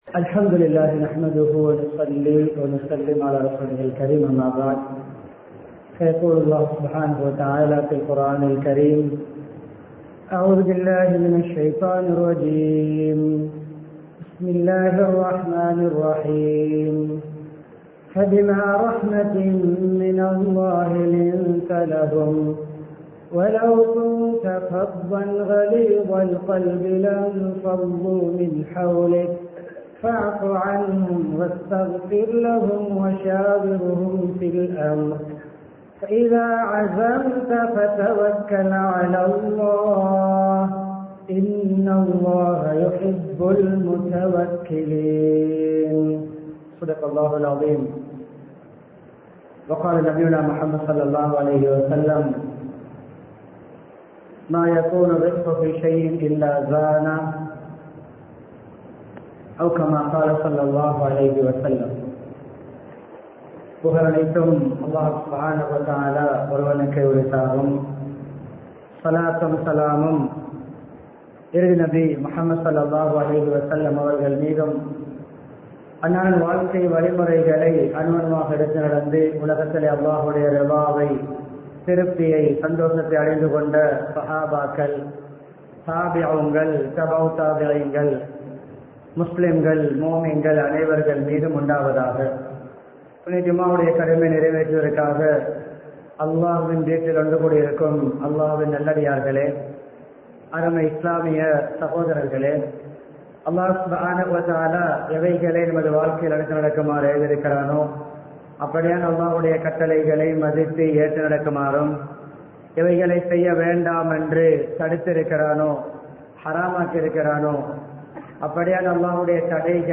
மென்மைத் தன்மையும் கடினமும் | Audio Bayans | All Ceylon Muslim Youth Community | Addalaichenai
Panadura, Horethuduwa Jumuah masjith